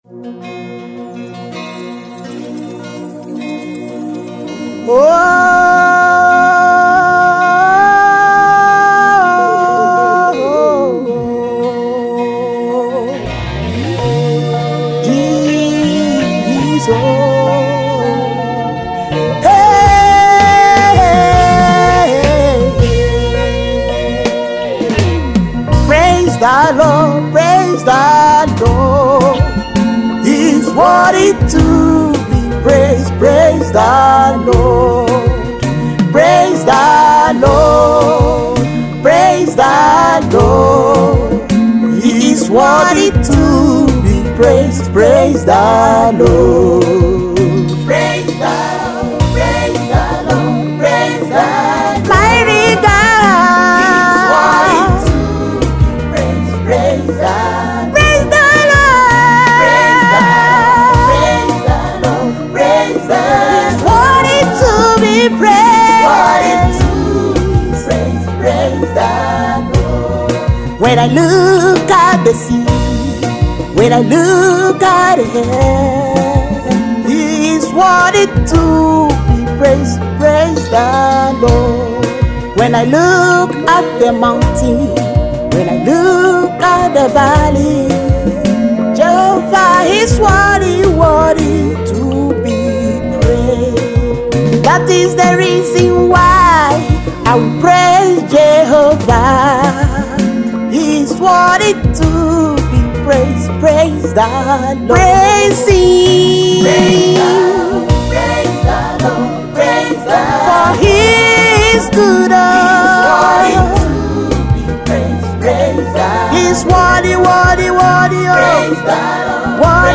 Emerging Gospel artist